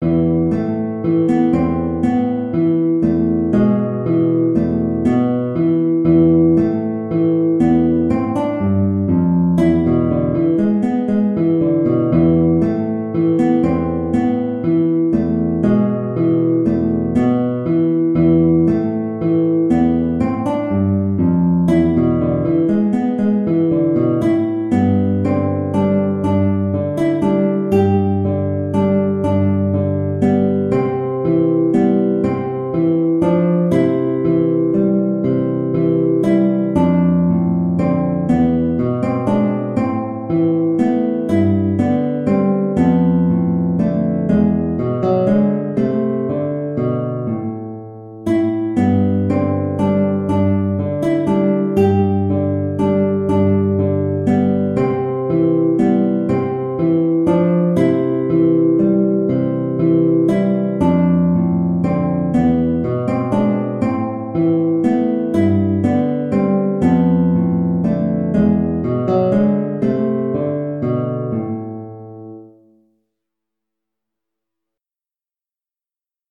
Adagio . = 40
6/8 (View more 6/8 Music)
E3-G5
Guitar  (View more Easy Guitar Music)
Traditional (View more Traditional Guitar Music)